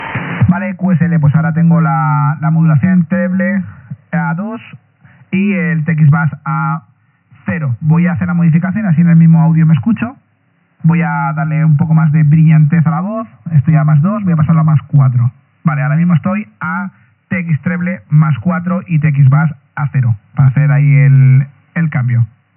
eSSB – Radio Hi-Fi
Equipación para conseguir un audio de estudio
La eSSB utiliza un ancho de banda de transmisión superior cuya limitación será la del propio equipo, hay equipos que dan 3khz, otros 5khz incluso hay equipos que con modificaciones de hardware son capaces de llegar a los 6khz.